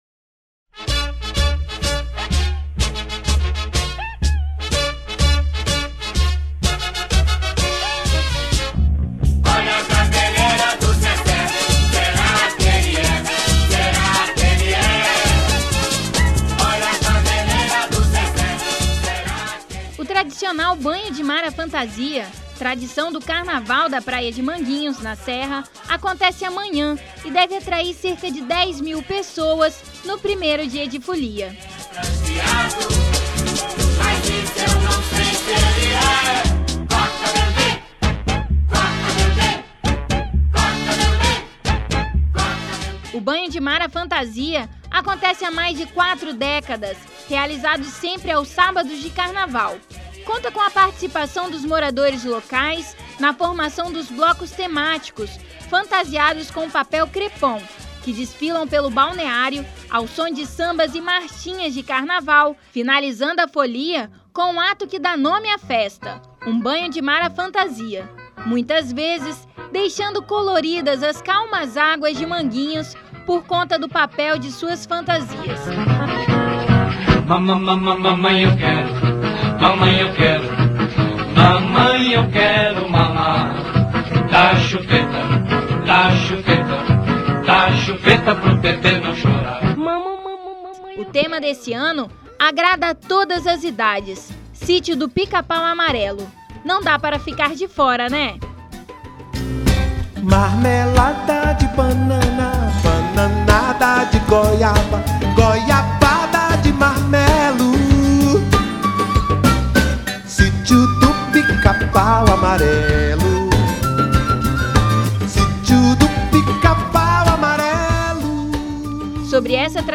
Confira a matéria que contém uma entravista com uma das organizadoras